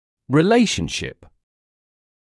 [rɪ’leɪʃnʃɪp] [ри’лэйшншип] взаимоотношение